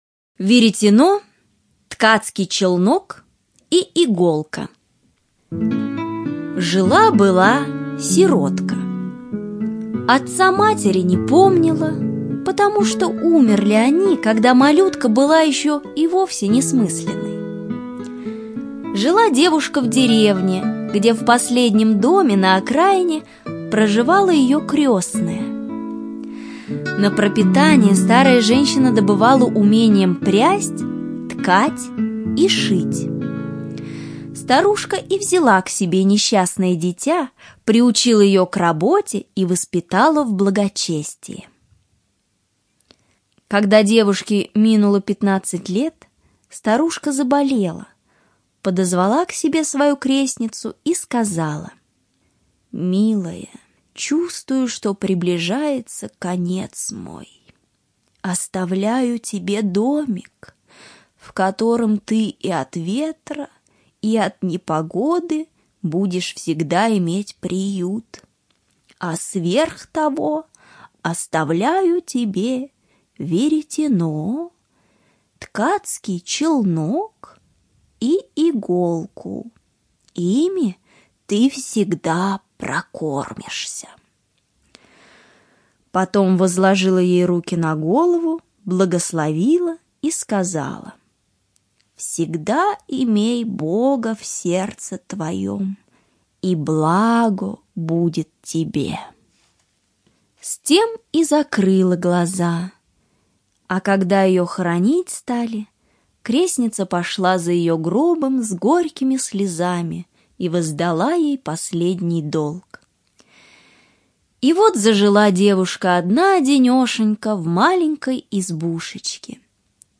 Слушайте онлайн Веретено, ткацкий челнок и иголка - аудиосказка братьев Гримм.